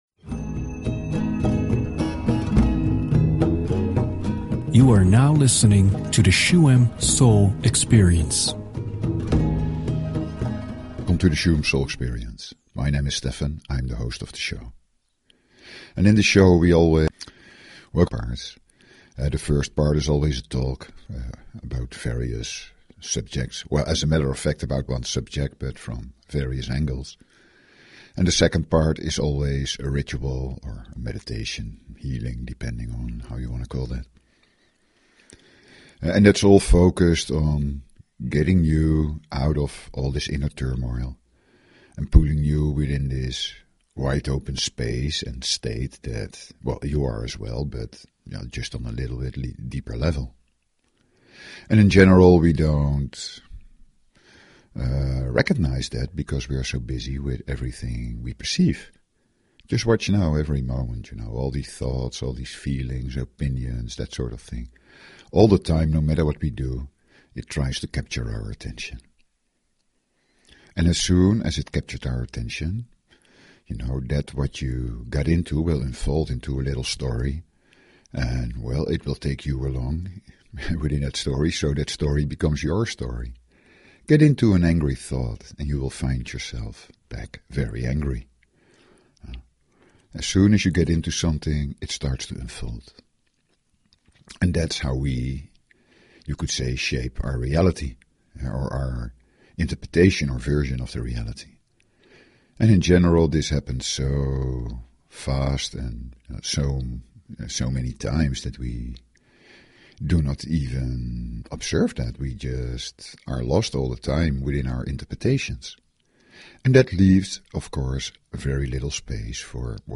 Talk Show Episode, Audio Podcast, Shuem_Soul_Experience and Courtesy of BBS Radio on , show guests , about , categorized as
Just let the sounds and silence take your thoughts away and enter a more subtle reality within yourself.